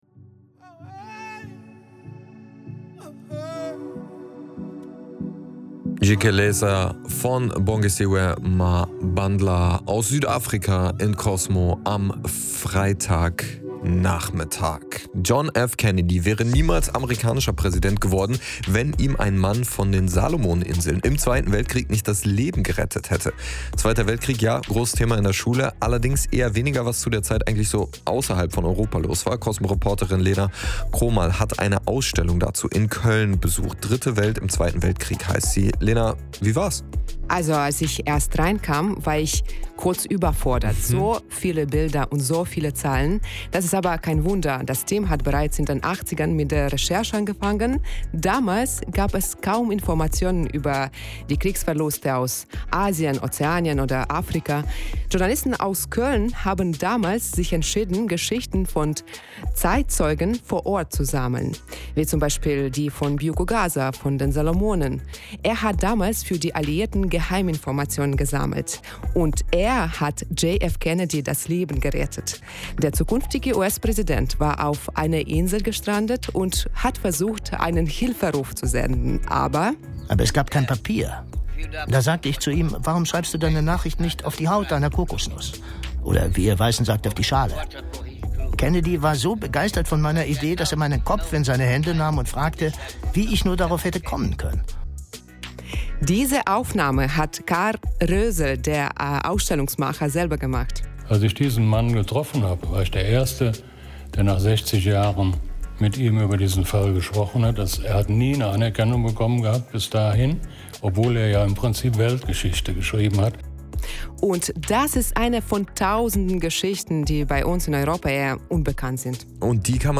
Rundfunkbeitrag von WDR-Cosmo (7. März) zur Eröffnung der Ausstellung